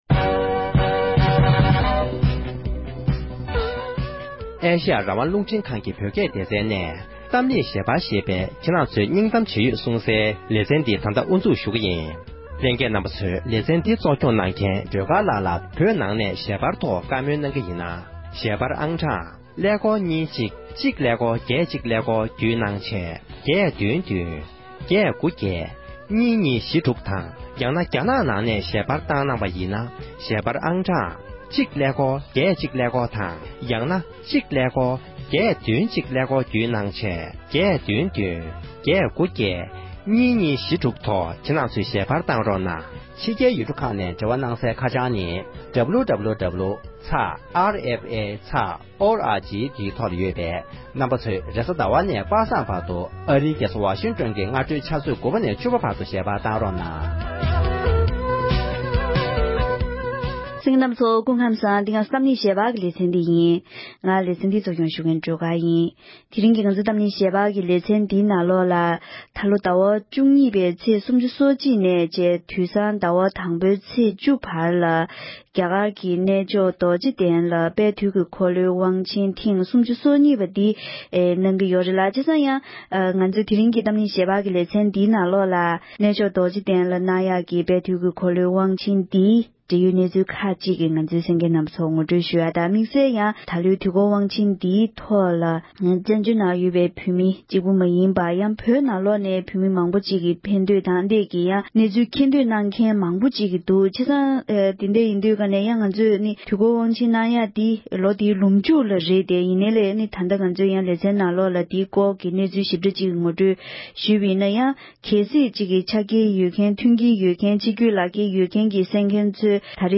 འབྲེལ་ཡོད་མི་སྣར་བཀའ་འདྲི་ཞུས་པ་ཞིག